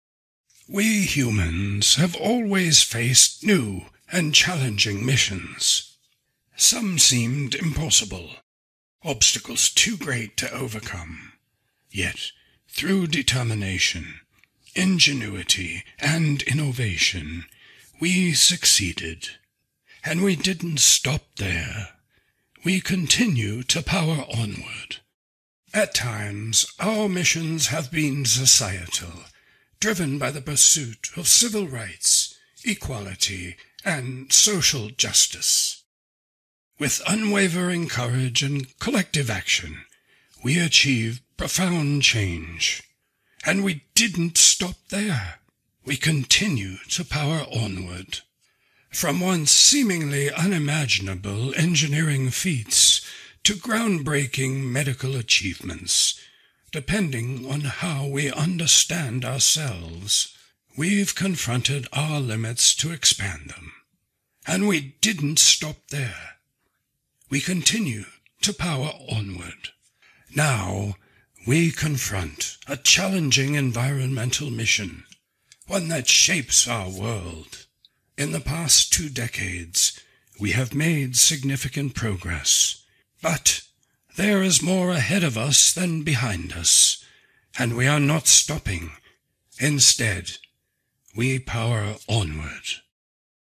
Sir David Attenborough Voice
Depending on the projects needs, I will record using a Sennheiser MKH416 or a TLM 103 mic in a sound studio with Avid Pro Tools Studio Edition.